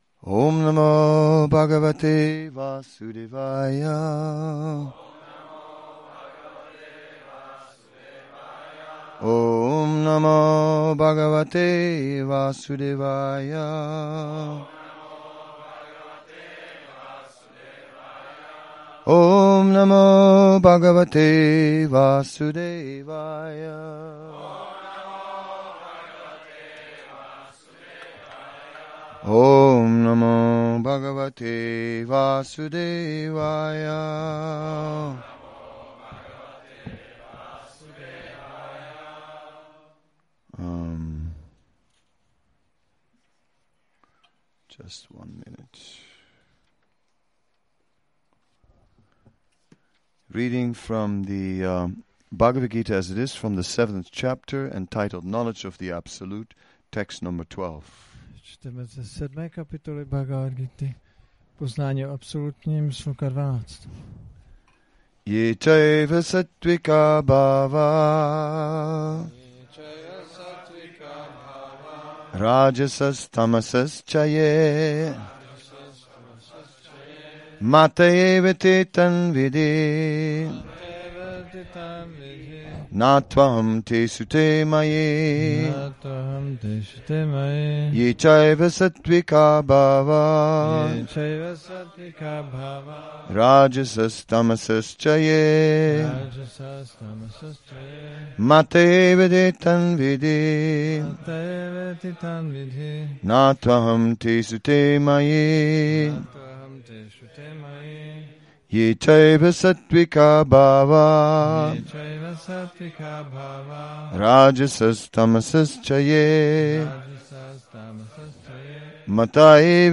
Přednáška BG-7.12 – Šrí Šrí Nitái Navadvípačandra mandir